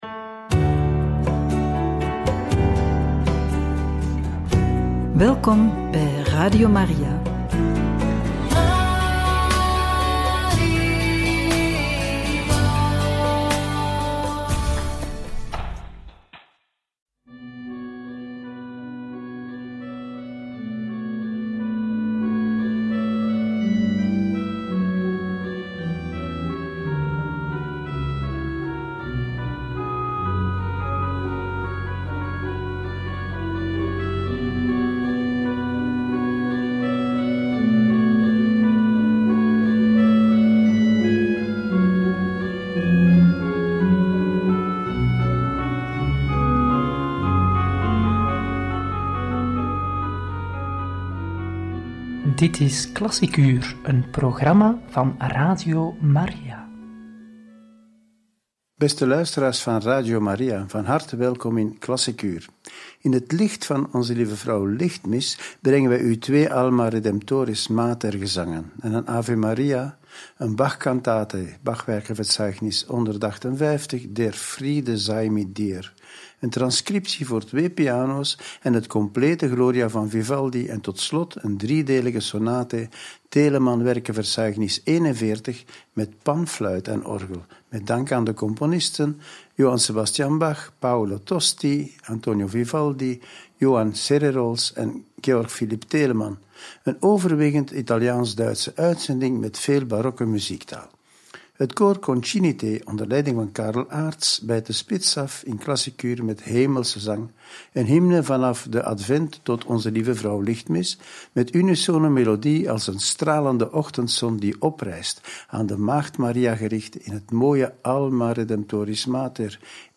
Een overwegend Italiaans/Duitse uitzending met veel barokke muziektaal – Radio Maria
donderdag-om-13u-een-overwegend-italiaans-duitse-uitzending-met-veel-barokke-muziektaal.mp3